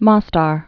(môstär)